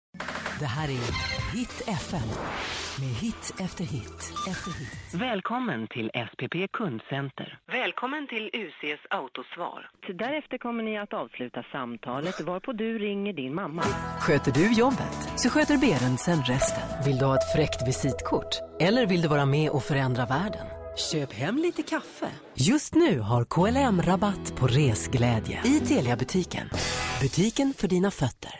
Schwedische weibliche stimme, Schwedischer VO, profi,
Sprechprobe: Werbung (Muttersprache):